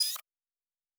pgs/Assets/Audio/Sci-Fi Sounds/Weapons/Additional Weapon Sounds 3_3.wav
Additional Weapon Sounds 3_3.wav